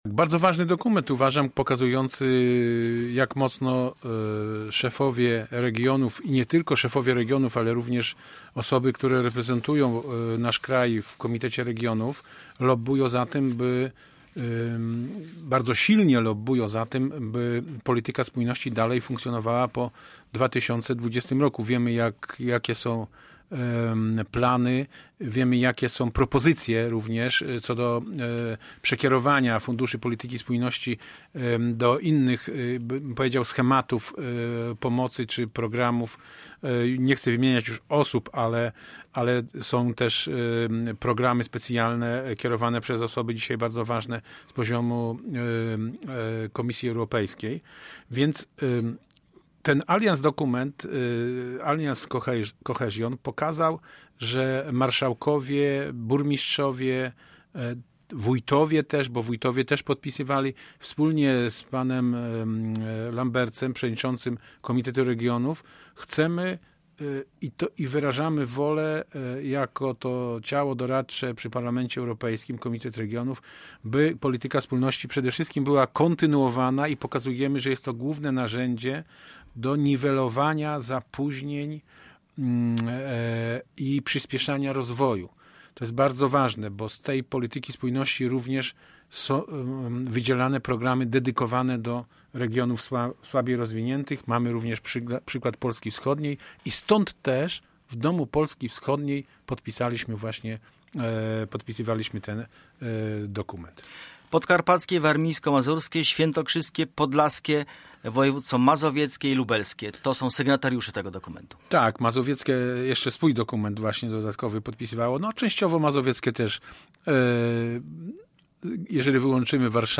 Sławomir Sosnowski był gościem piątkowego (09.02) programu Centrum Uwagi.